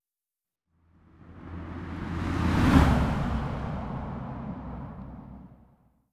Pasada de coche con efecto doppler